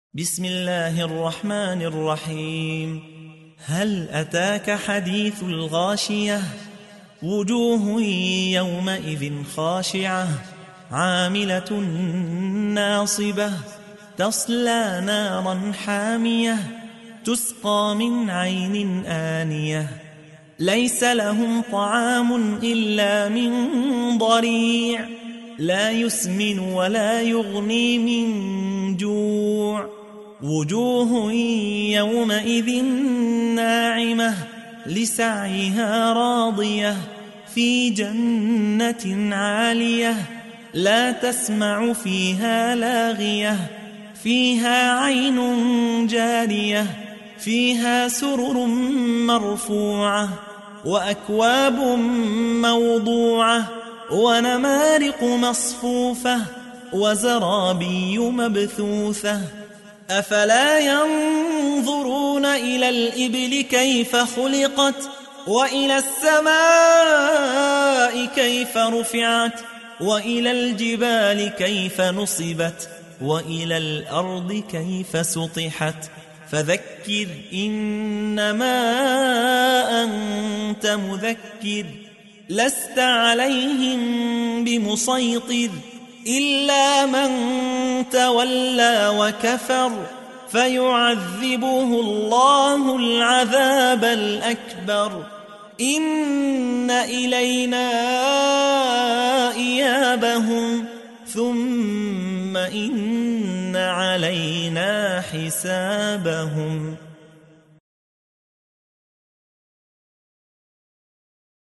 تحميل : 88. سورة الغاشية / القارئ يحيى حوا / القرآن الكريم / موقع يا حسين